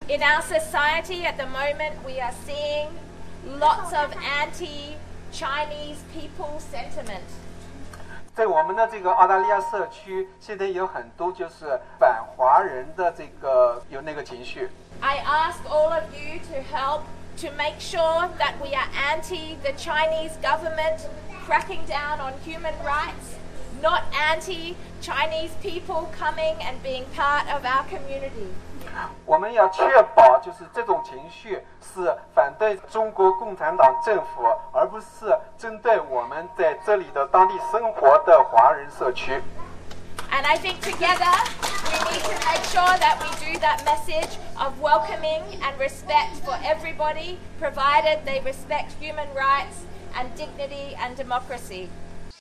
上周日是天安门事件28周年纪念日，悉尼的一些华人在昨天下午举办了题为"当前中国形势与六四"的座谈会，并于晚上在中国驻悉尼总领馆外进行了烛光集会。新州议会代表Newtown的议员Jenny Leong女士在烛光集会现场谈到了澳洲社会反华人的情绪的现状。